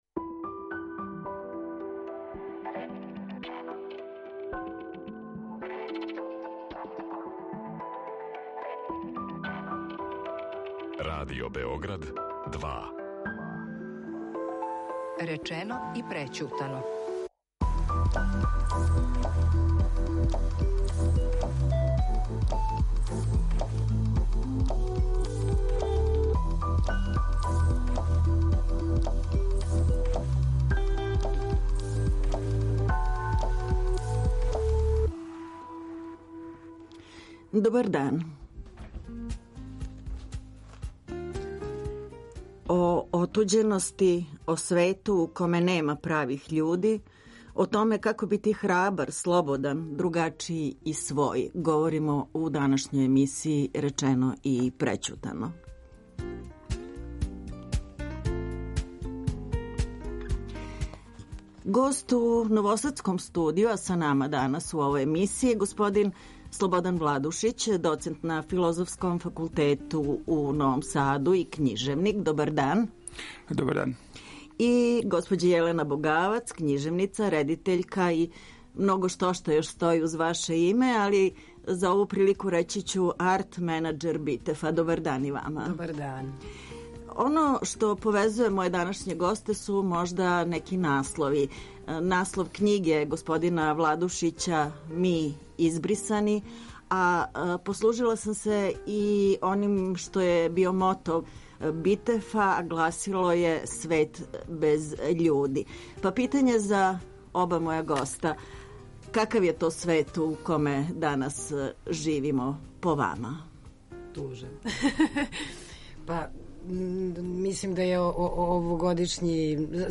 Извор: Радио Београд 2